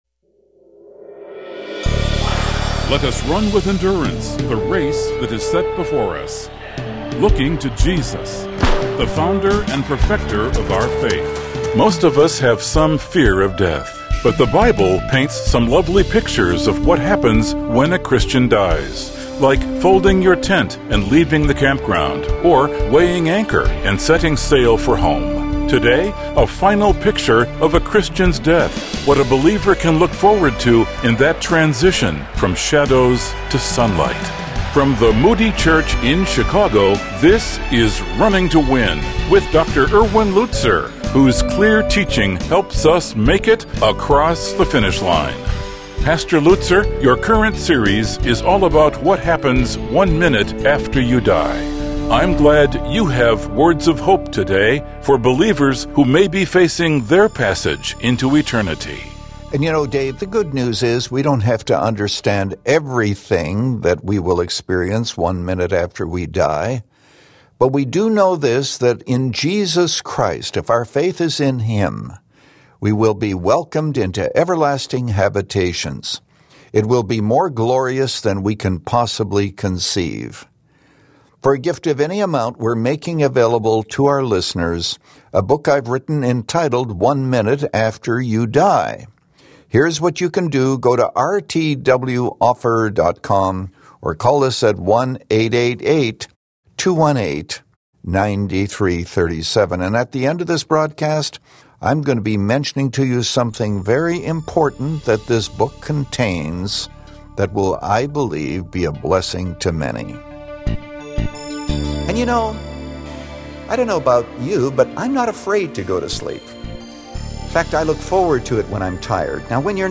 But the Bible paints some lovely pictures of what happens when a Christian dies. In this message, Pastor Lutzer highlights three lessons from the death of a believer.